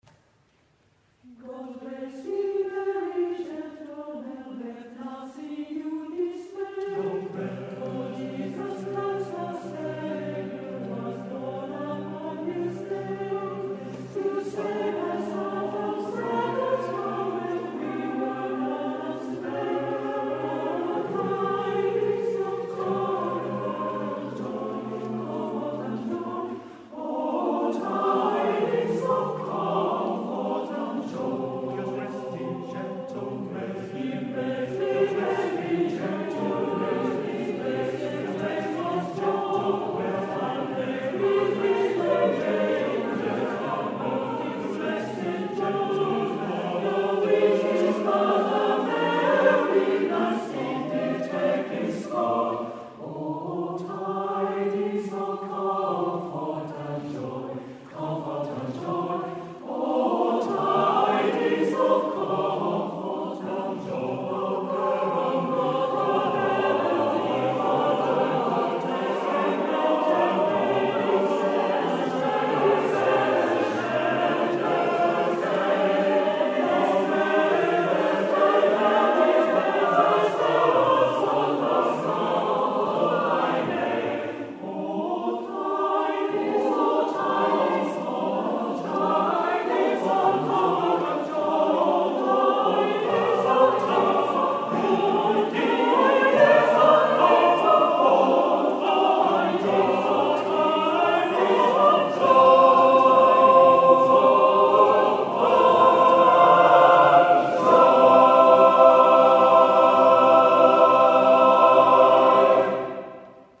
渋谷区文化総合センター大和田